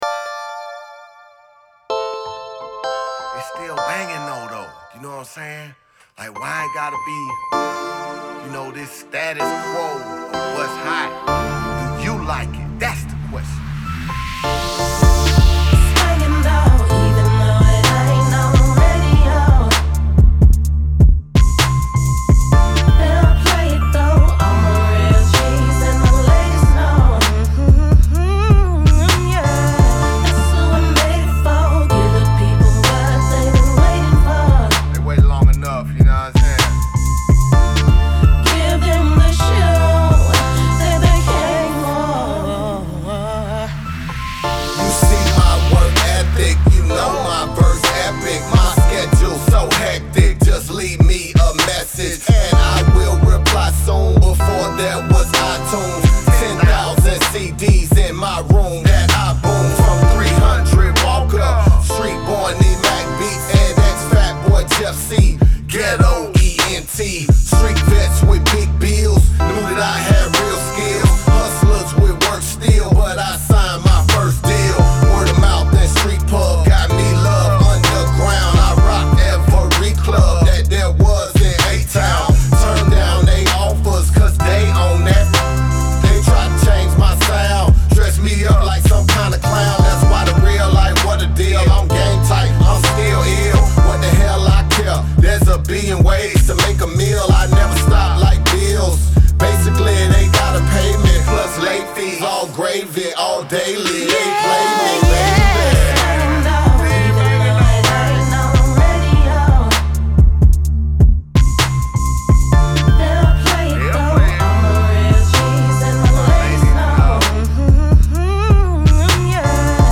(Tracked Out Mix + Master)